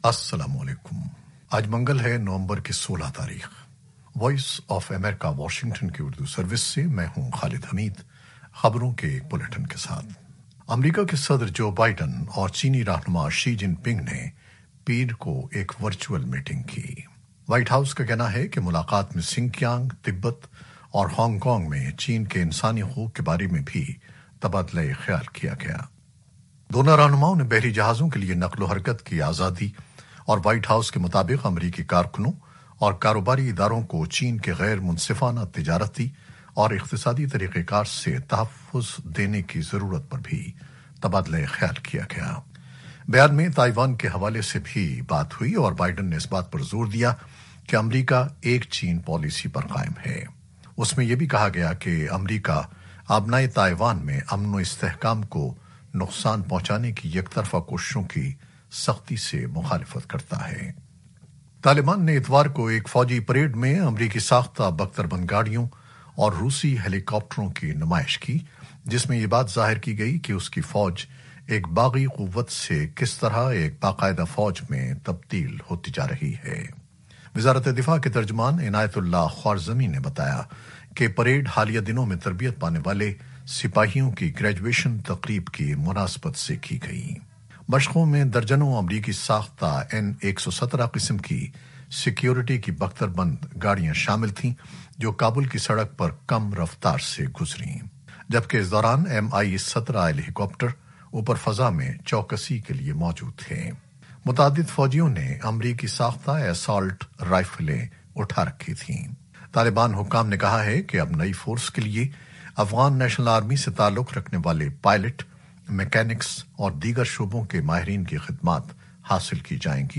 نیوز بلیٹن 2021-16-11